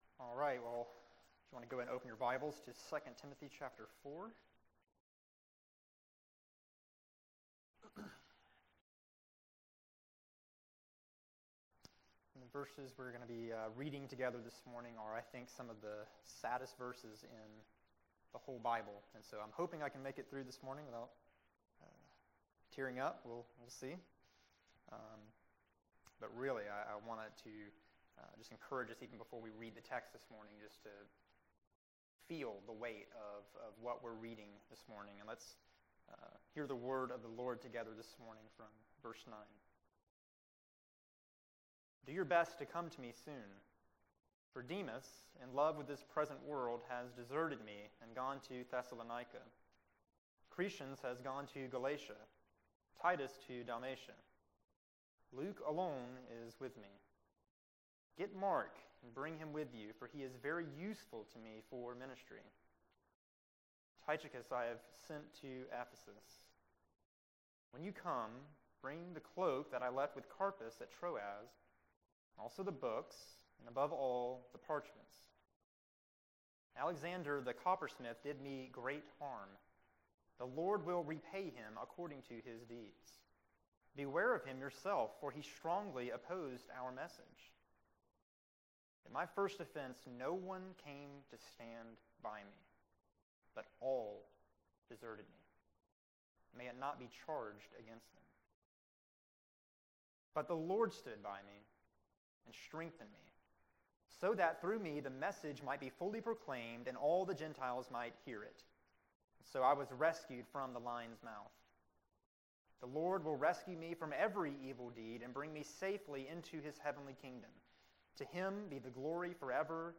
May 8, 2016 Morning Worship | Vine Street Baptist Church